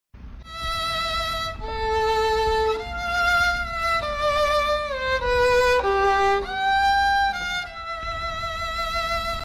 Part 5 | Sad Violin sound effects free download